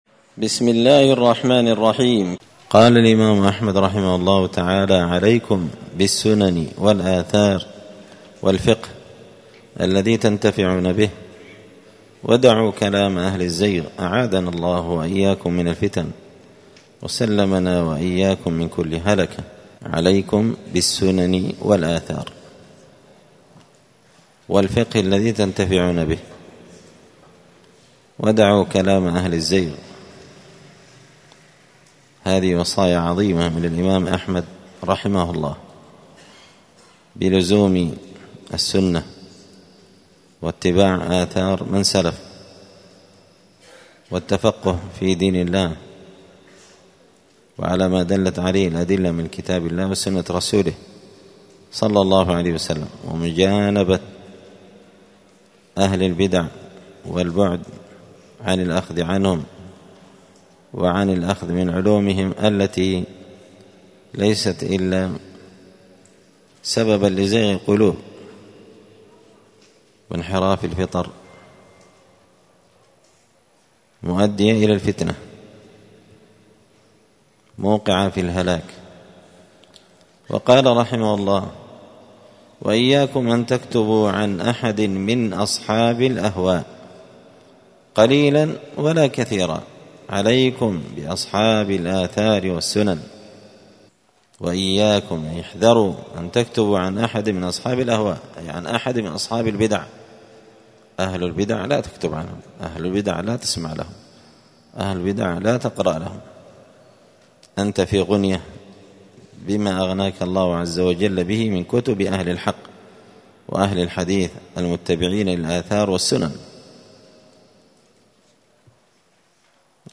دار الحديث السلفية بمسجد الفرقان بقشن المهرة اليمن
الأحد 18 جمادى الآخرة 1445 هــــ | الدروس، الفواكه الجنية من الآثار السلفية، دروس الآداب | شارك بتعليقك | 58 المشاهدات